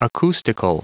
Prononciation du mot acoustical en anglais (fichier audio)
Prononciation du mot : acoustical